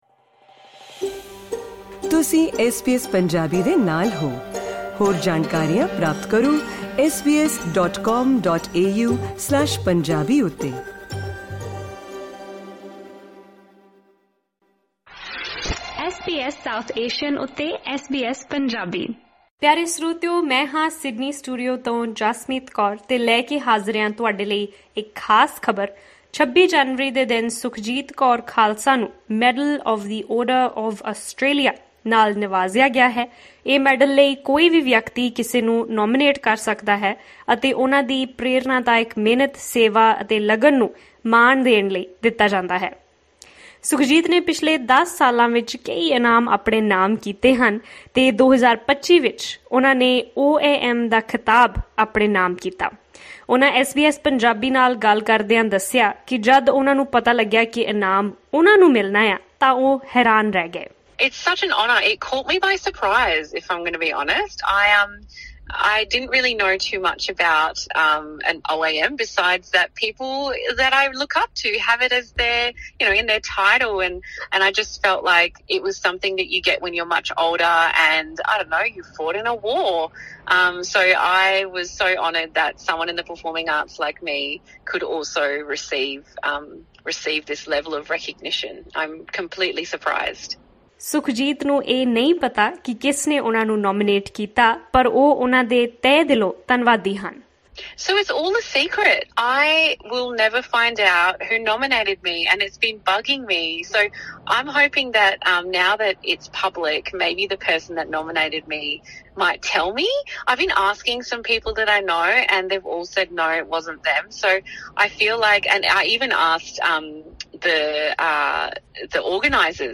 Click on the audio icon to listen to the full report and interview in Punjabi.